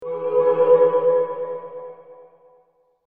Звуки вспышки